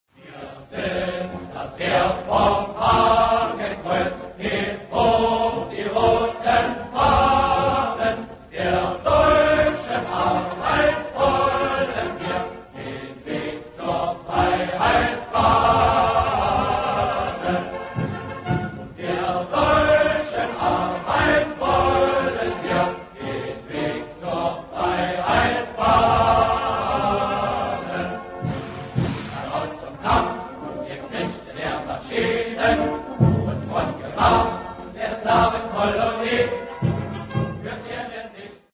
Вслушайтесь в то, что эти друзья там напевают: